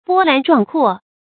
波澜壮阔 bō lán zhuàng kuò
波澜壮阔发音
成语注音 ㄅㄛ ㄌㄢˊ ㄓㄨㄤˋ ㄎㄨㄛˋ
成语正音 澜，不能读作“làn”。